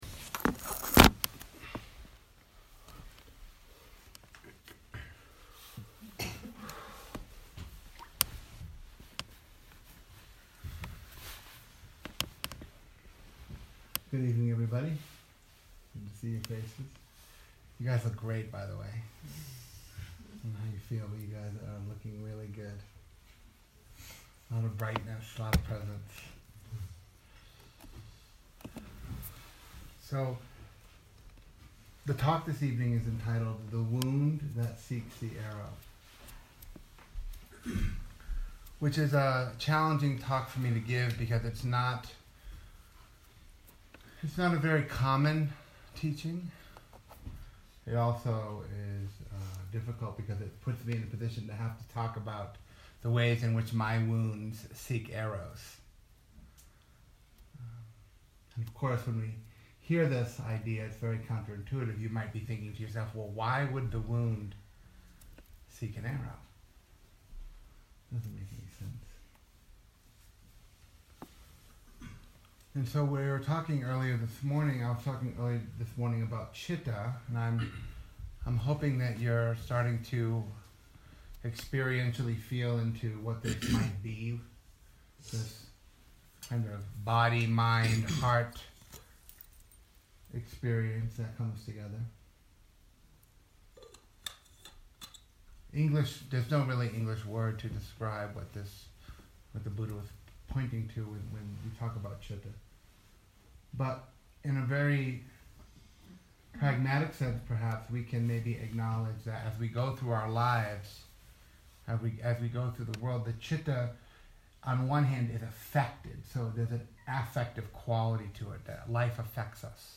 This talk was given on the 3rd night of the Vallecitos retreat in June 2018. It explores Dukkha-Sankhara, how our unexplored core wounds, give rise to unsuccessful and destructive patterns of thought.